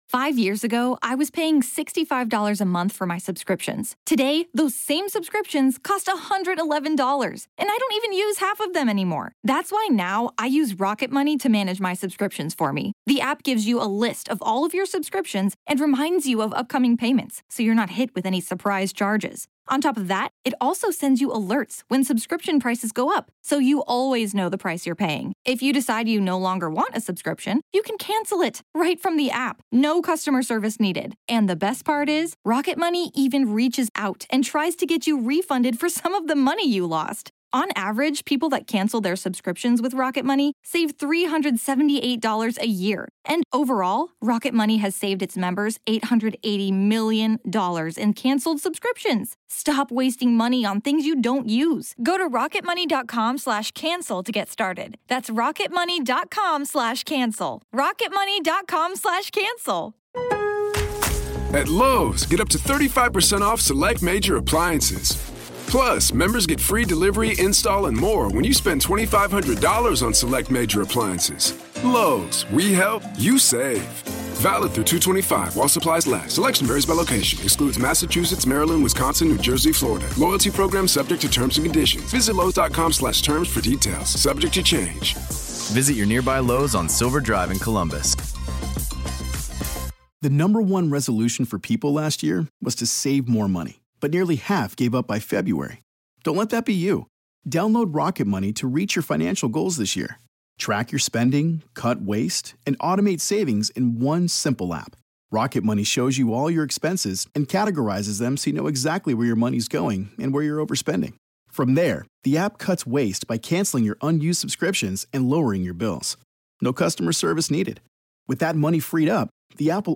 Bridget Marquardt Interview 🪦 Grave Talks Classic